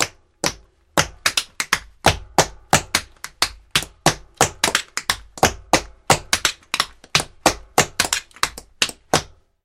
Звуки чечётки
Чечётка - Ещё для разнообразия